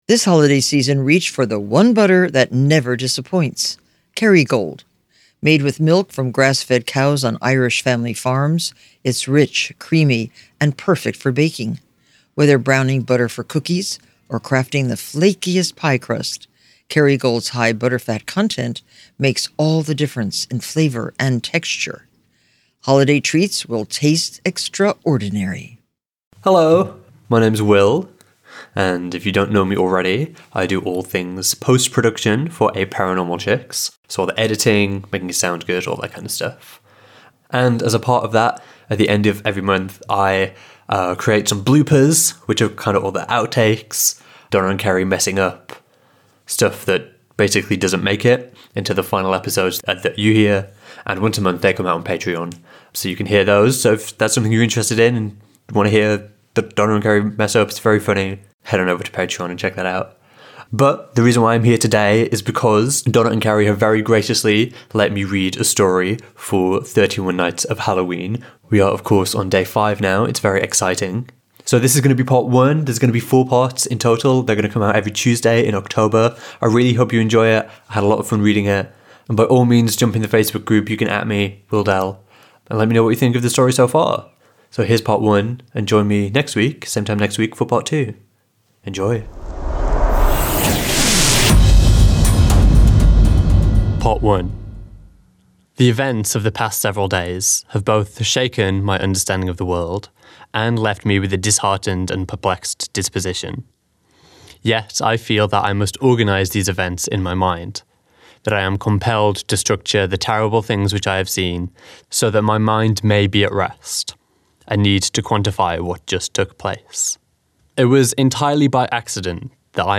read a story for you